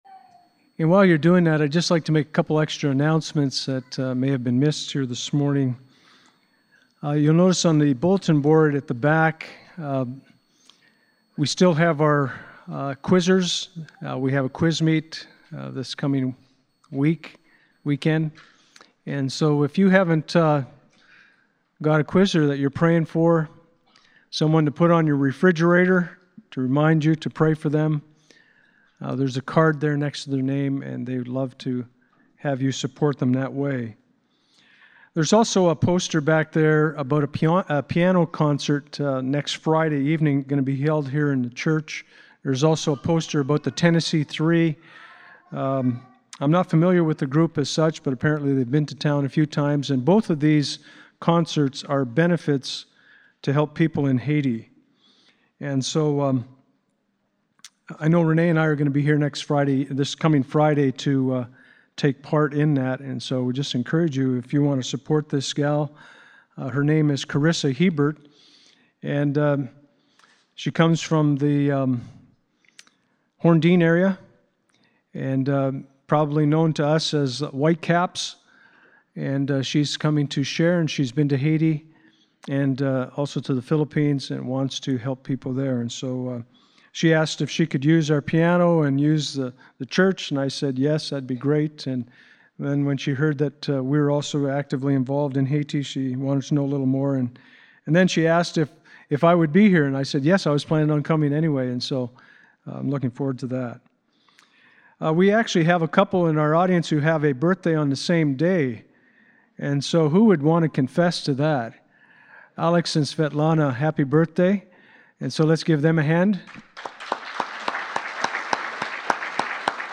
Passage: 2 Corinthians 1:8-24 Service Type: Sunday Morning